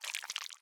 Minecraft Version Minecraft Version snapshot Latest Release | Latest Snapshot snapshot / assets / minecraft / sounds / block / honeyblock / slide2.ogg Compare With Compare With Latest Release | Latest Snapshot
slide2.ogg